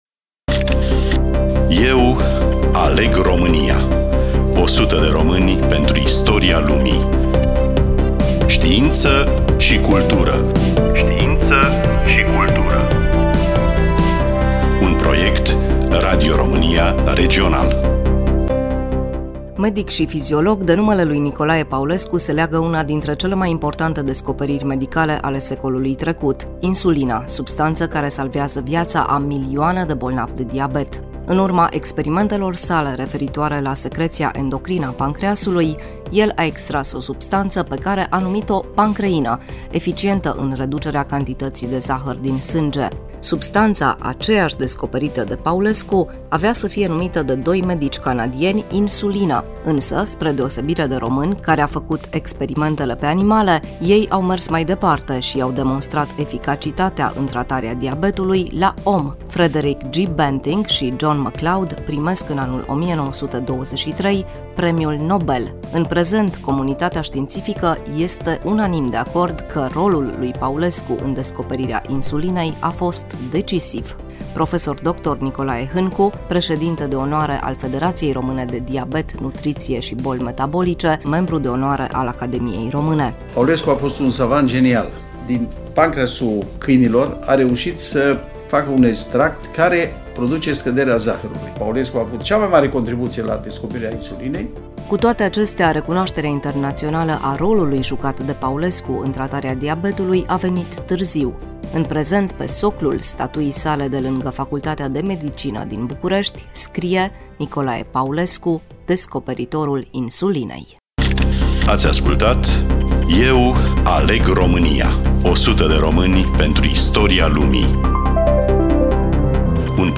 Studioul: Radio Romania Cluj